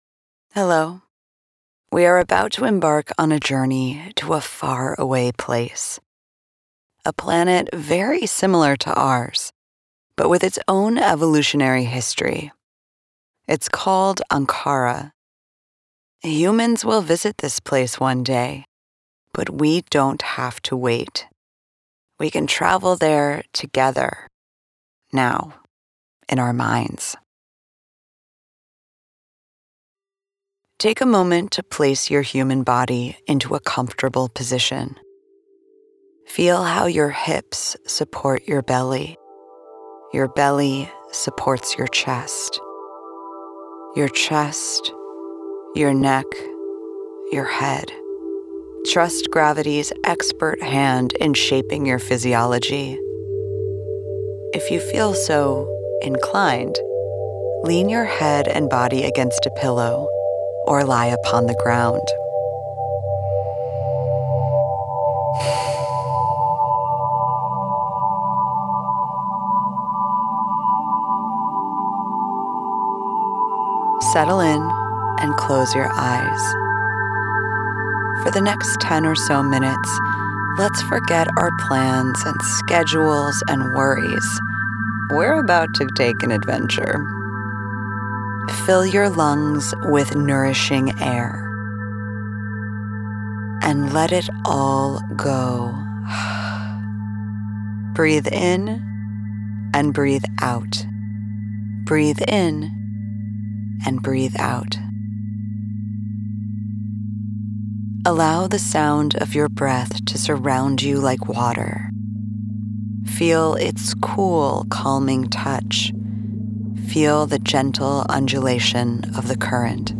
About Tubopus: The following meditation will guide you to the distant planet of Ankhara, a fantastical world from the Spacetime Diaries universe. You will merge your consciousness with a pasta-shaped deep sea creature, and experience a day in its life. The soundscape you’ll hear during the intro is a sonification of data received from NASA's Chandra X-ray Observatory. It is a sonic representation of the deepest x-ray images of space we have.